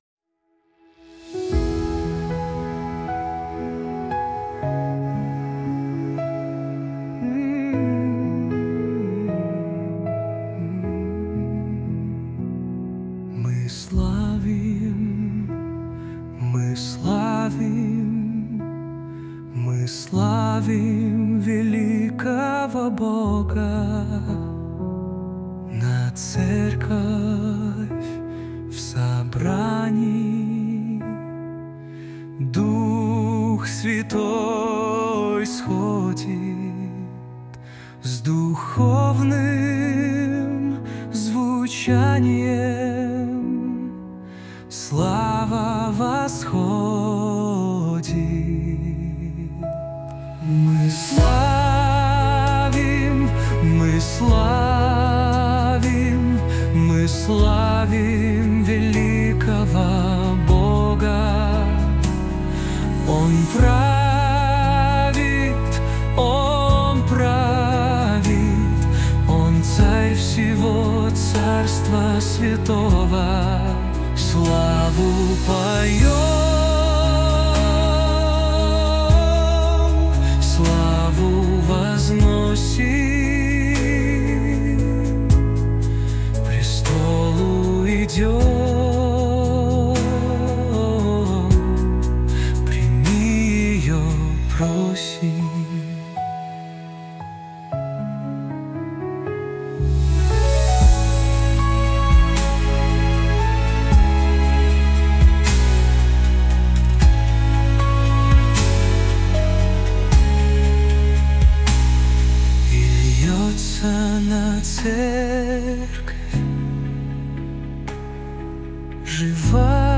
песня ai
136 просмотров 883 прослушивания 30 скачиваний BPM: 77